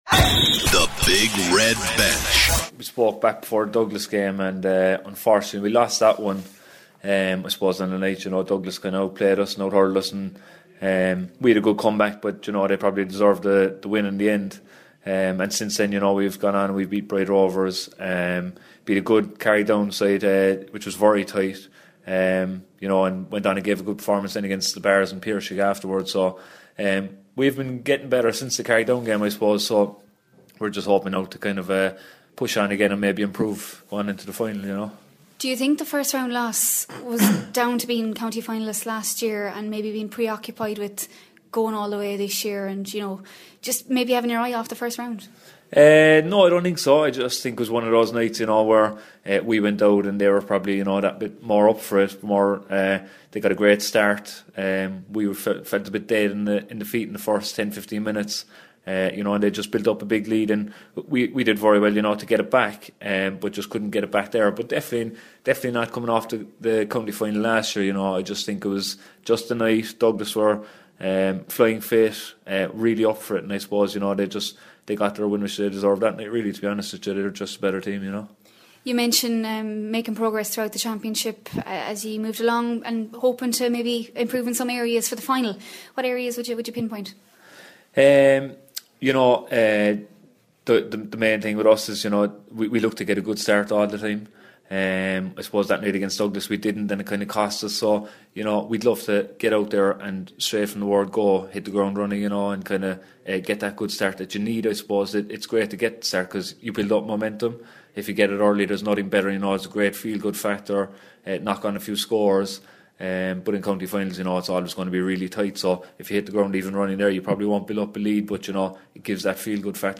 County Final Preview - Extended Sarsfields Interviews